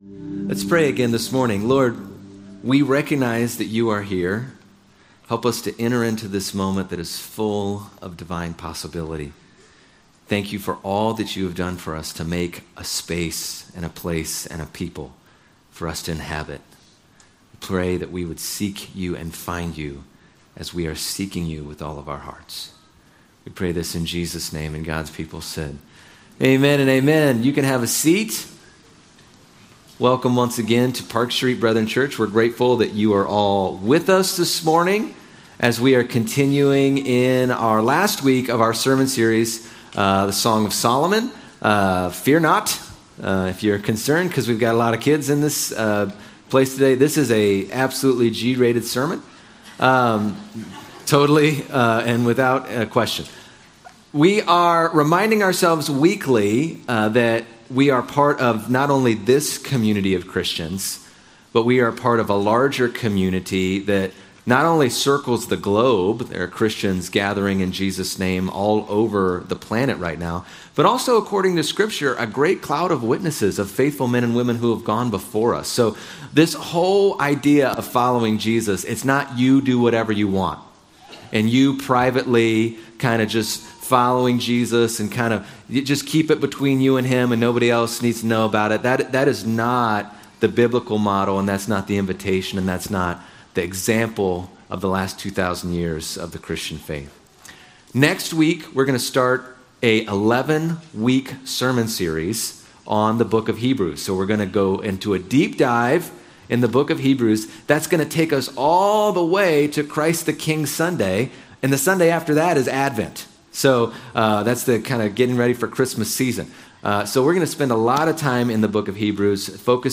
Sermons - Park Street Brethren Church